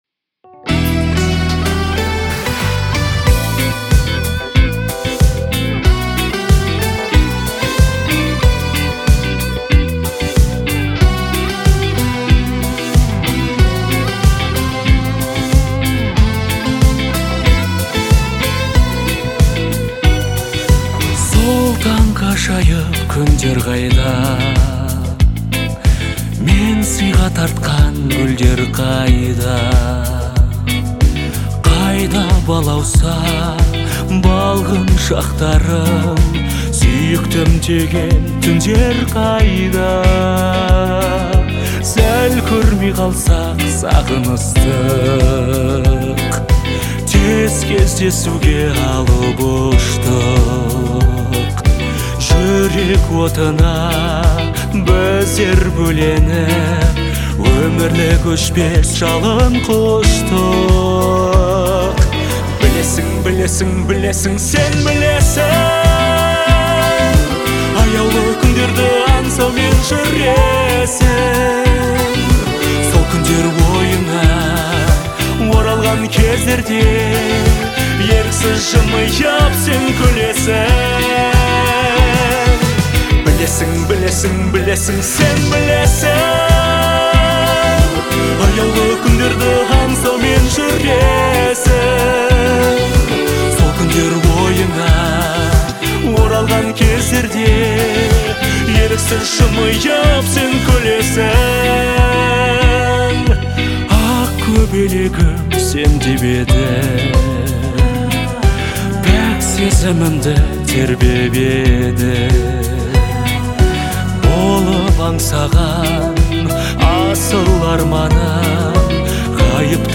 Звучание песни отличается мелодичностью и душевностью